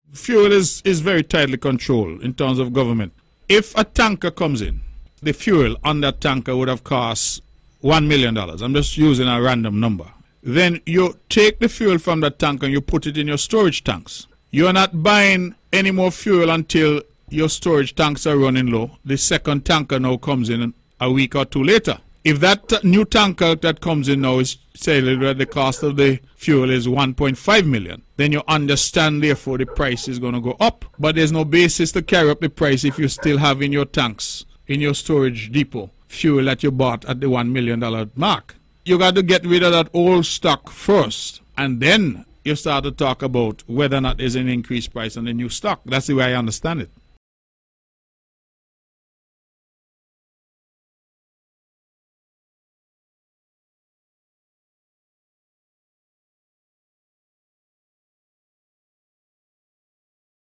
Meantime, Premier of Nevis, the Hon. Mark Brantley, explained on Apr. 8th, the Government still has control over the prices: